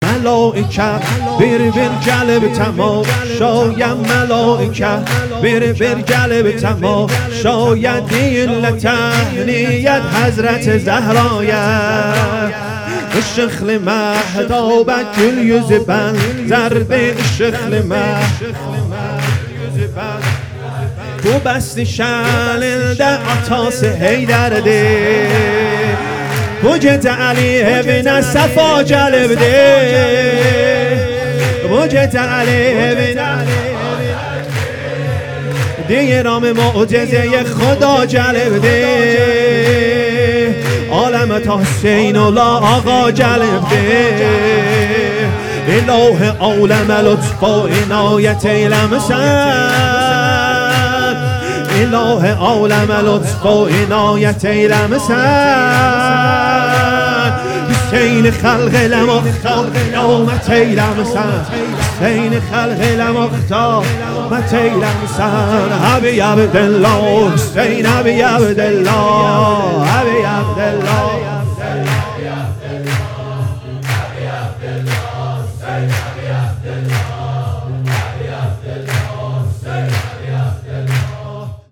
سرود |قدم گویوب خاکه
ولادت امام حسین(ع) محبین عقیلة العرب(س) تبریز